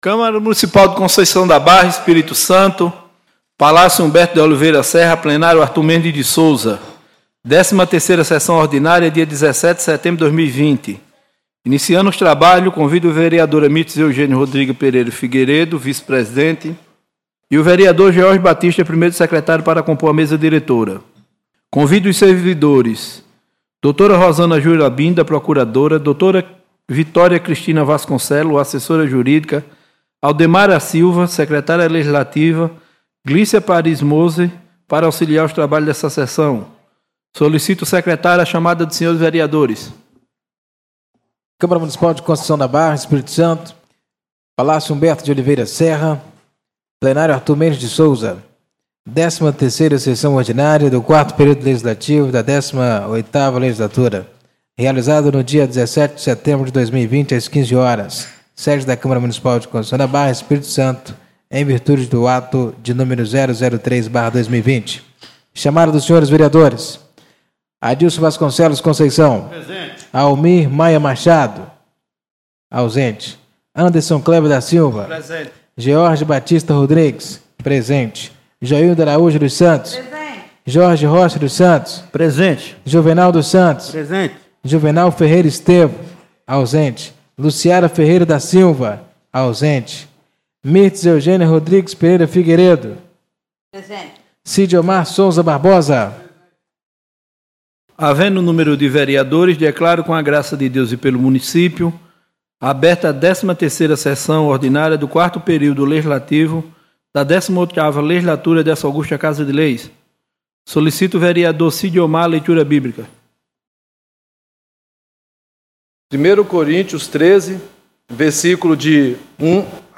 13ª Sessão Ordinária do dia 17 de Setembro de 2020